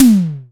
077 - Tom-2.wav